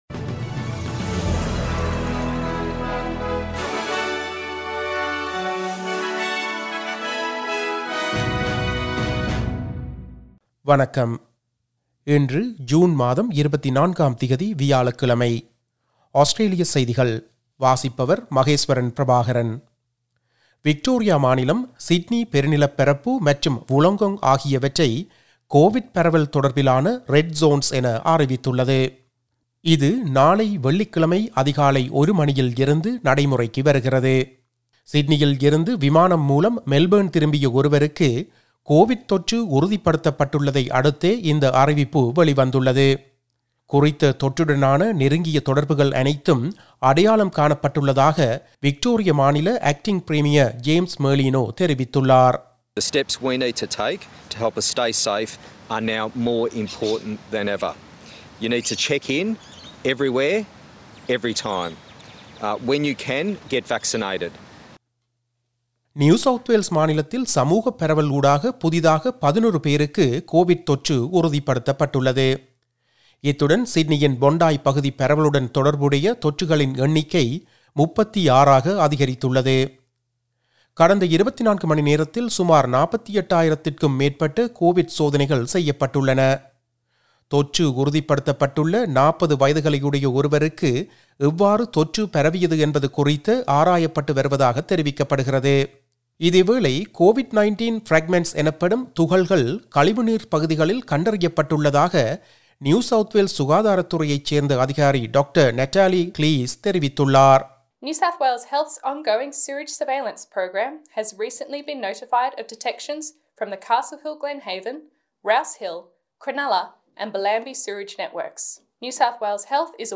Australian news bulletin for Thursday 24 June 2021.